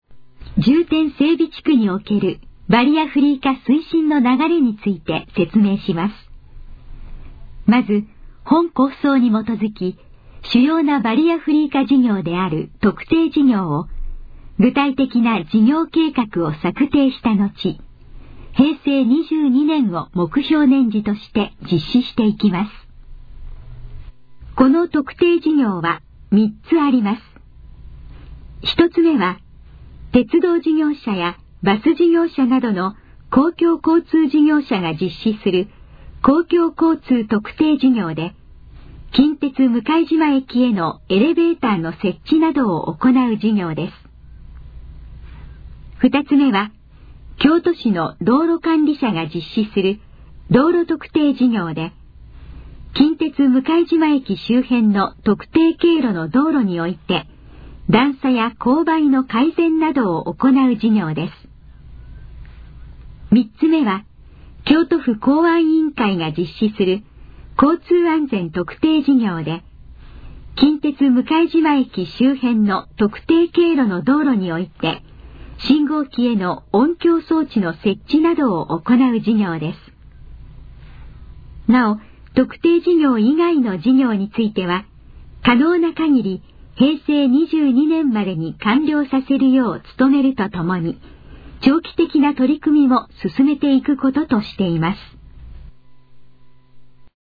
このページの要約を音声で読み上げます。
ナレーション再生 約196KB